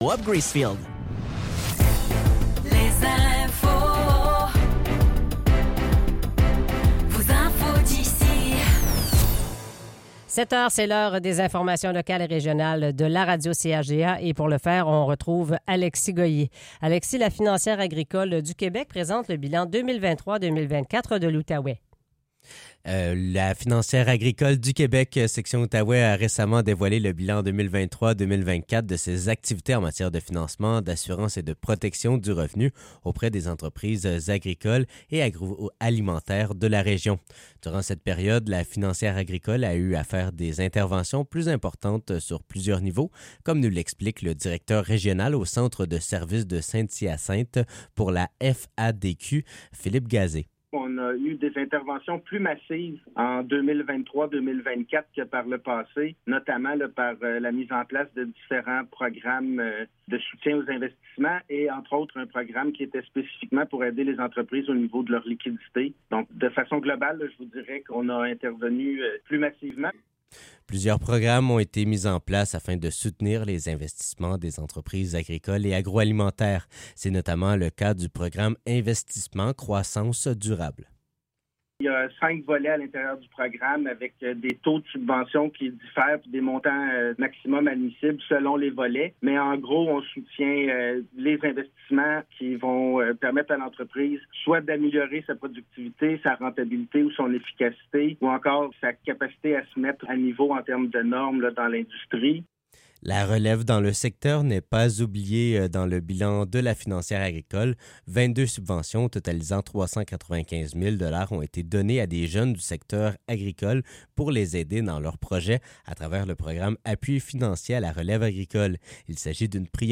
Nouvelles locales - 26 novembre 2024 - 7 h